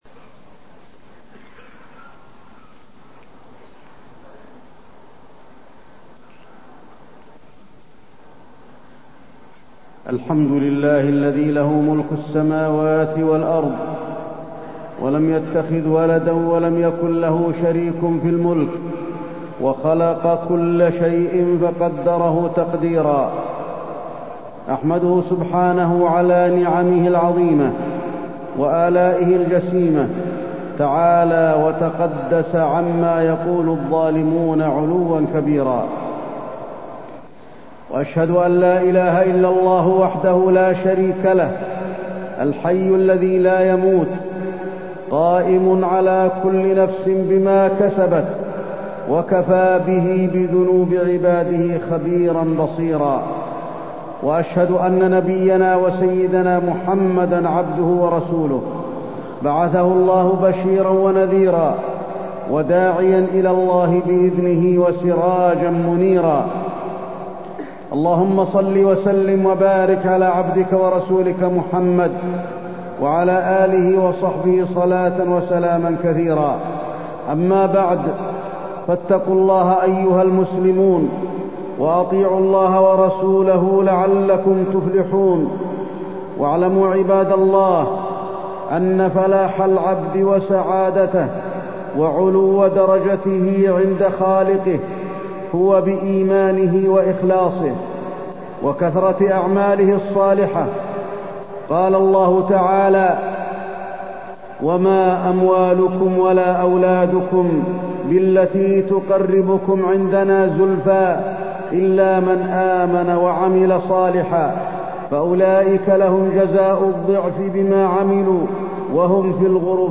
تاريخ النشر ٢٠ صفر ١٤٢٣ هـ المكان: المسجد النبوي الشيخ: فضيلة الشيخ د. علي بن عبدالرحمن الحذيفي فضيلة الشيخ د. علي بن عبدالرحمن الحذيفي العمل الصالح